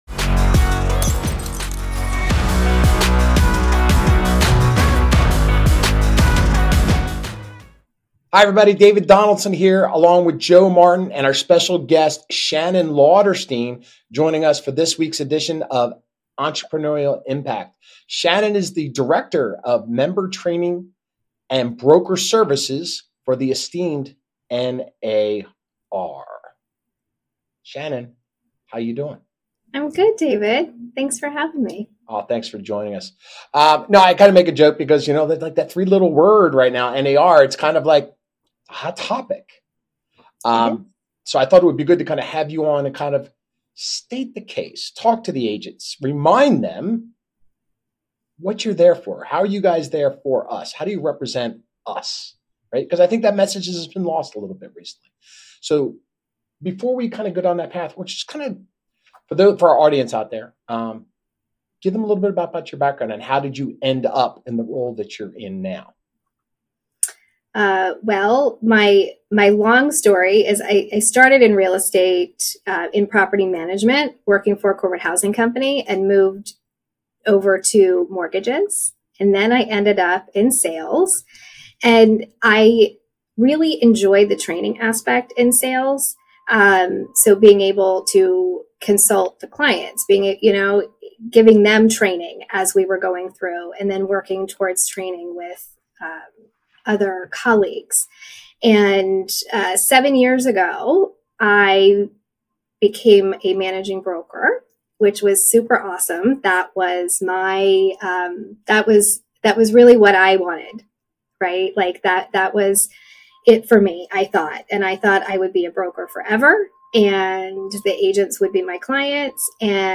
They discuss key benefits like education, advocacy, and the Code of Ethics, which define NAR's role in elevating real estate standards. This engaging conversation emphasizes the need for better communication about NAR’s value to members and consumers alike.